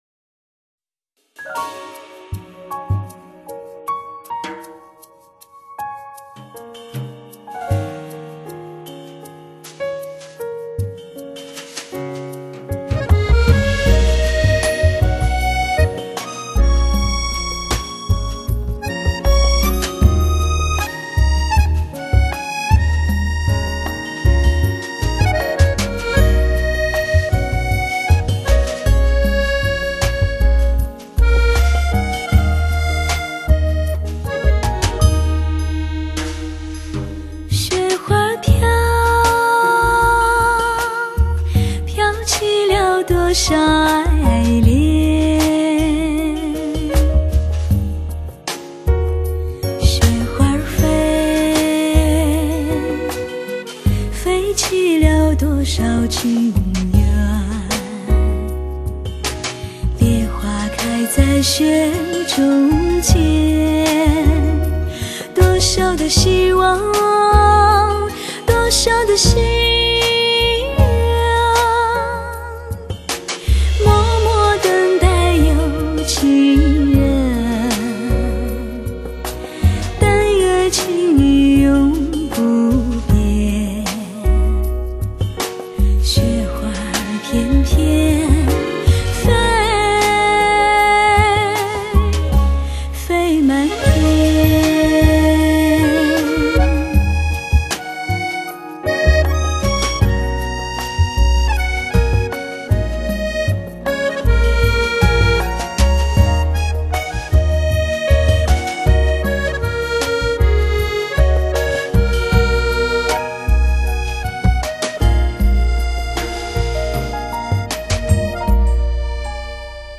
最醇美的声音 最纯洁的情感 顶级发烧 超值享受